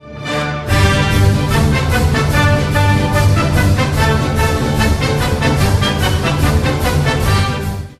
Music
Artist Jacques Offenbach / Slovak State Philharmonic Orchestra, Johannes Wildner
The sample is short in relation to the duration of the recorded track and is of an inferior quality to the original recording.